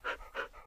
wolf_panting.ogg